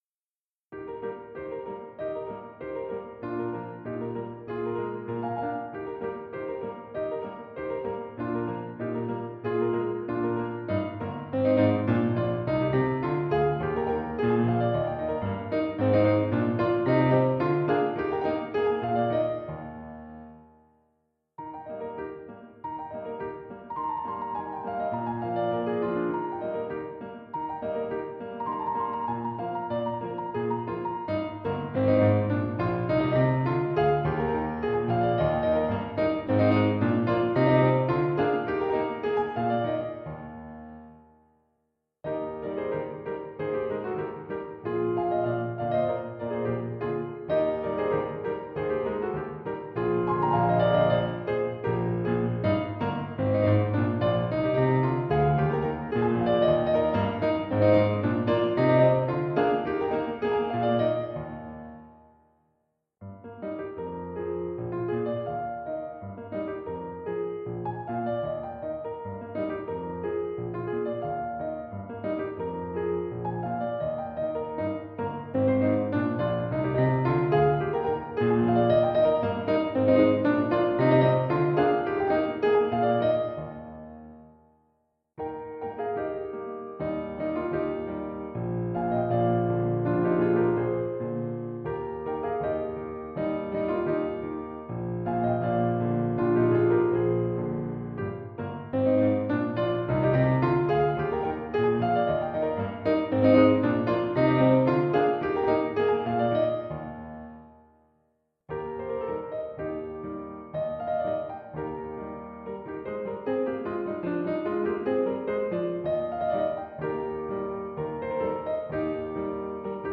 No parts available for this pieces as it is for solo piano.
Piano  (View more Easy Piano Music)
Classical (View more Classical Piano Music)